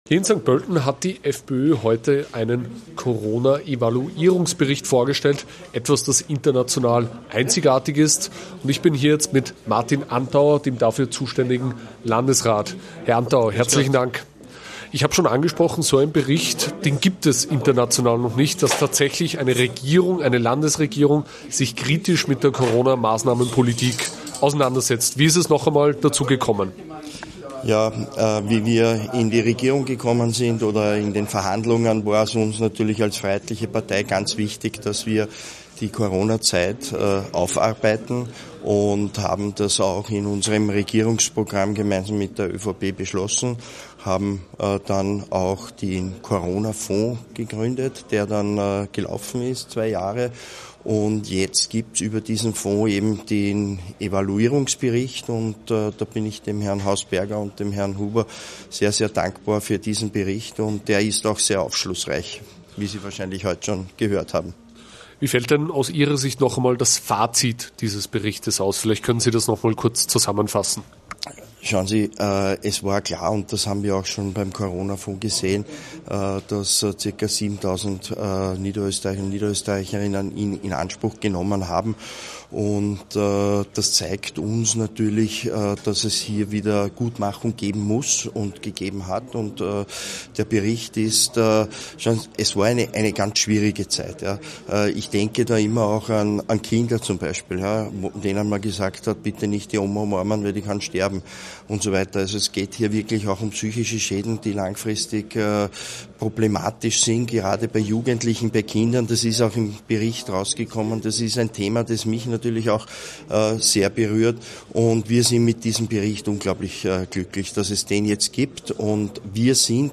daraus folgen müssen, erläutert er exklusiv im Gespräch mit AUF1.